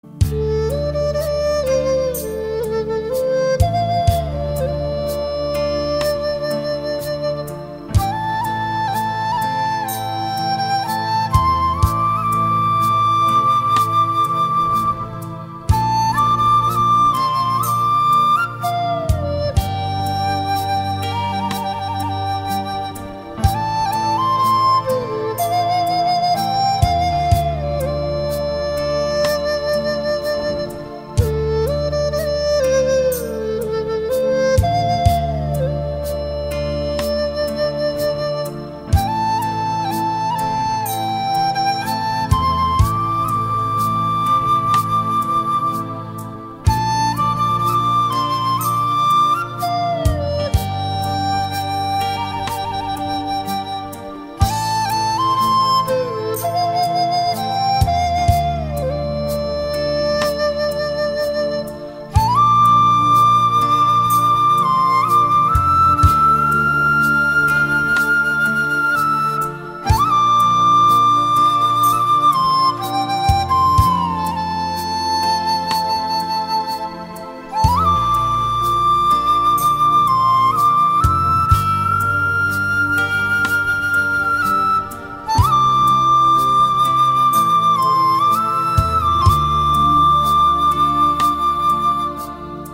hào hùng, phóng khoáng